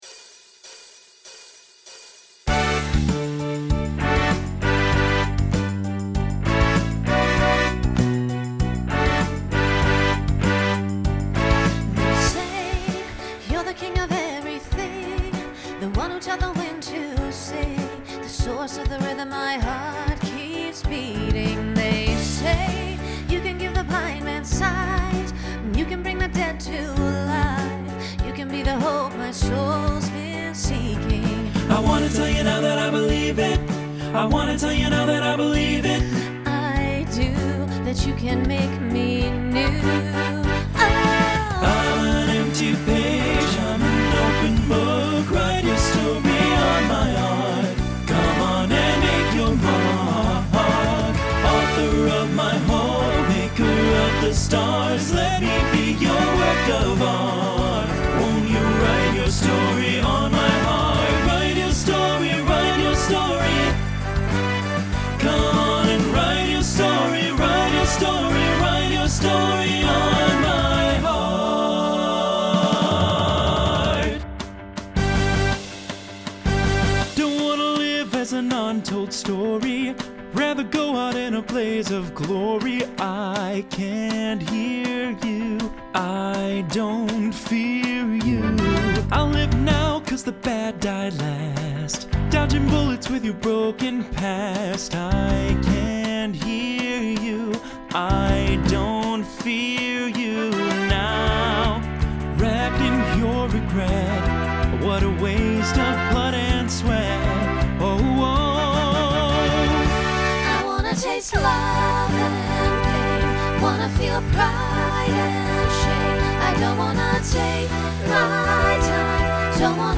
Starts with a treble solo, then TTB, then SSA, then SATB.
Genre Pop/Dance , Rock
Transition Voicing Mixed